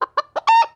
animal
Chicken Crow